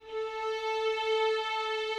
Added more instrument wavs
strings_057.wav